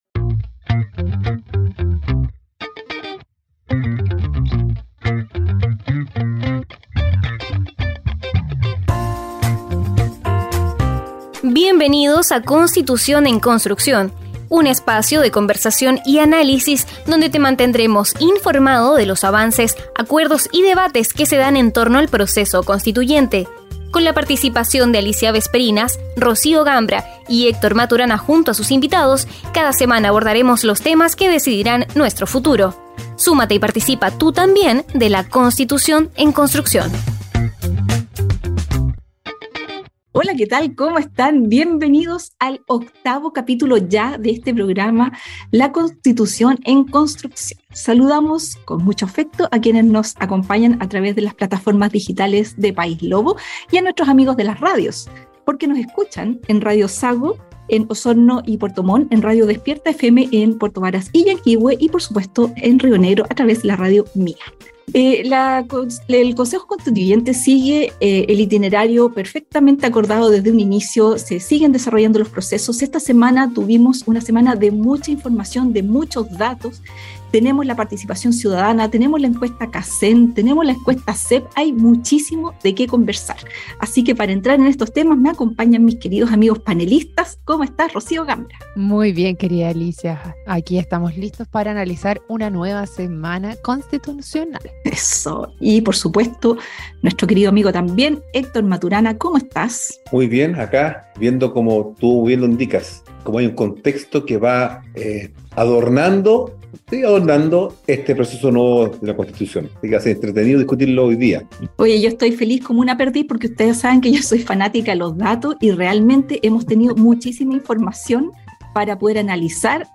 Además, contarás con la participación de nuestros destacados panelistas.